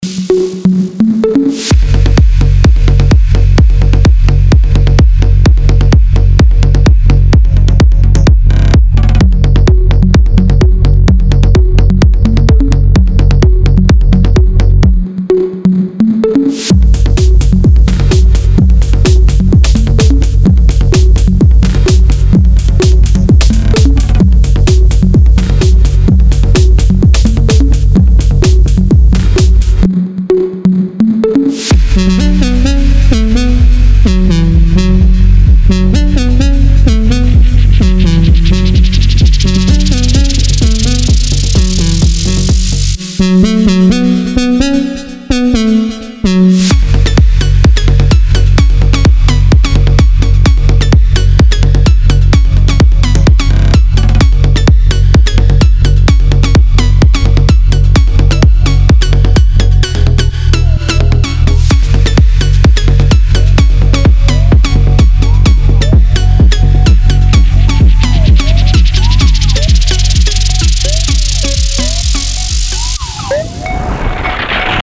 • Качество: 320, Stereo
ритмичные
громкие
EDM
без слов
electro house
Стиль - house